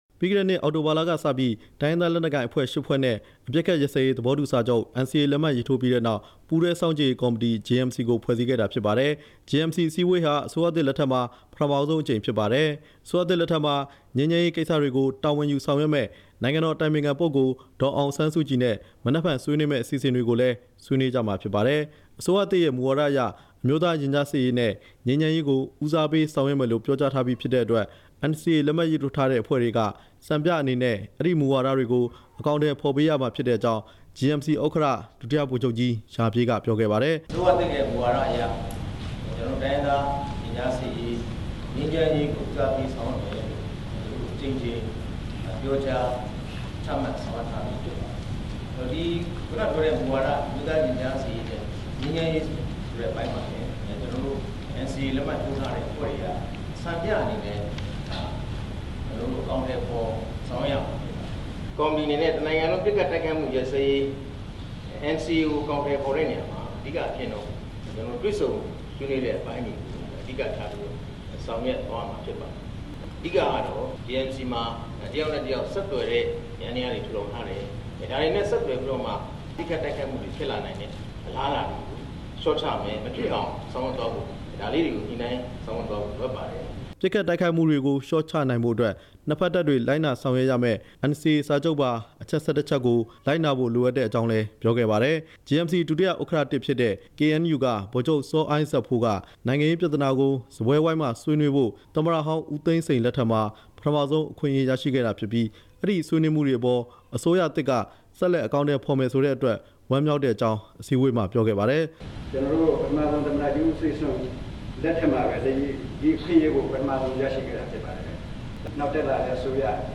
ဒီနေ့ နေပြည်တော်က Horizon Lake View ဟိုတယ်မှာ ကျင်းပတဲ့ ပြည်ထောင်စုအဆင့် ပစ်ခတ်တိုက်ခိုက်မှု ရပ်စဲရေးဆိုင်ရာ ပူးတွဲစောင့်ကြည့်ရေးကော်မတီ JMC အစည်းအဝေးမှာ JMC ဥက္ကဌ ဒုတိယဗိုလ်ချုပ်ကြီး ရာပြည့် က ပြောတာဖြစ်ပါတယ်။